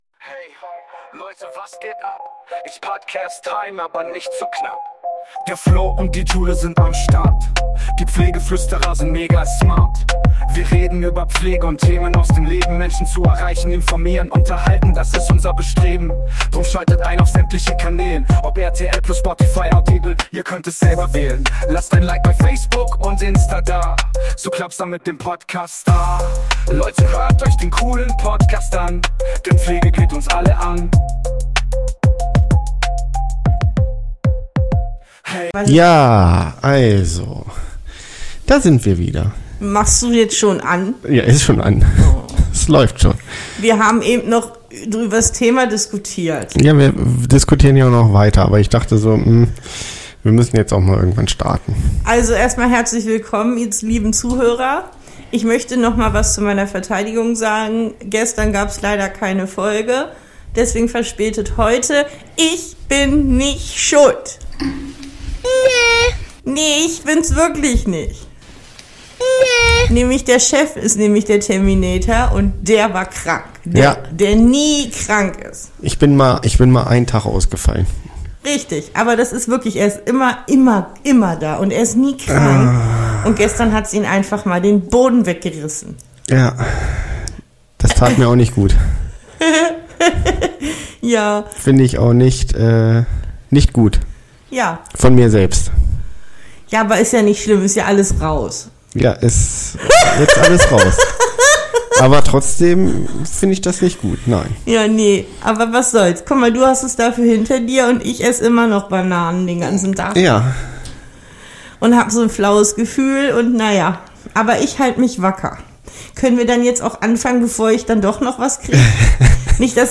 Aber hört rein bei unserem Plausch und Rausch, ja noch ein wenig angeschlagen aber dennoch lustig.